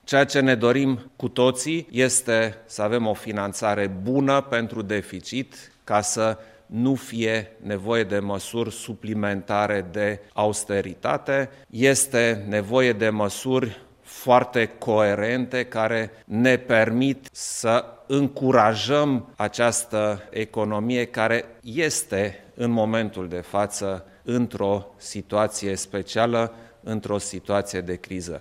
Anunțul a fost făcut de preşedintele Klaus Iohannis, care a precizat că se caută soluţii pentru acoperirea acestuia: